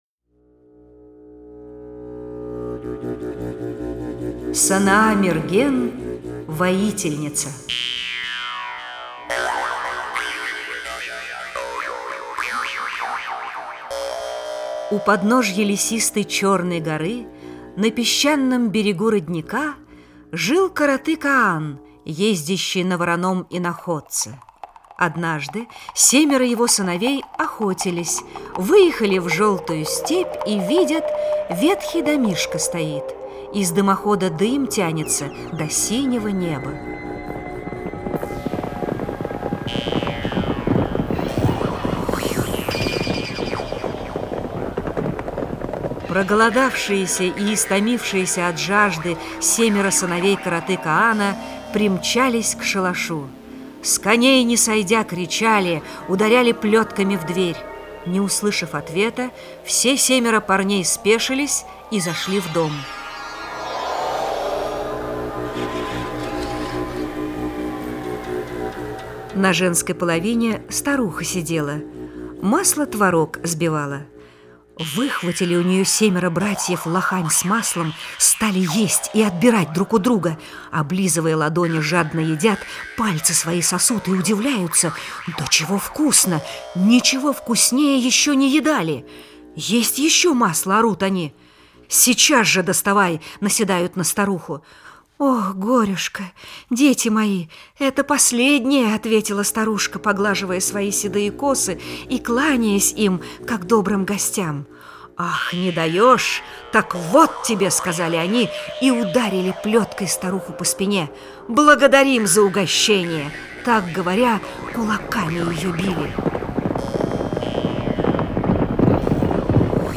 Санаа-Мерген воительница - алтайская аудиосказка - слушать онлайн